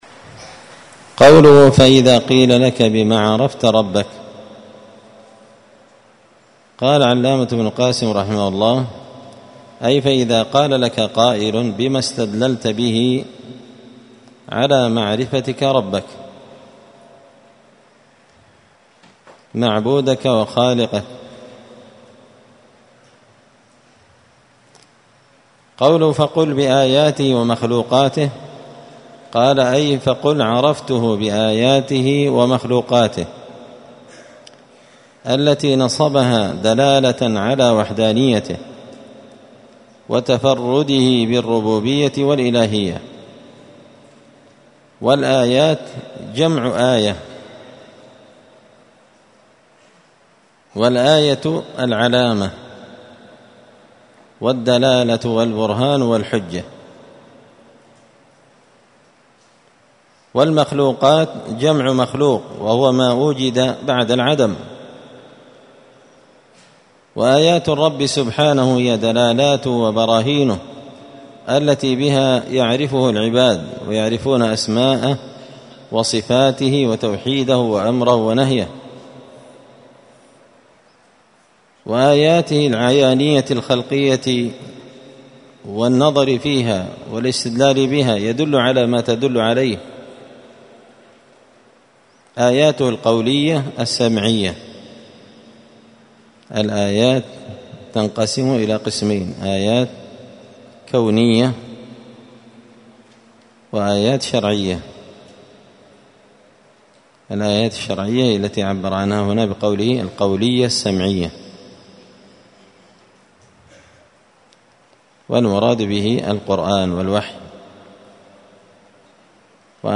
*الدرس الحادي عشر (11) من قوله {فإذا قيل لك بم عرفت ربك فقل بآياته ومخلوقاته ومن آياته الليل…}*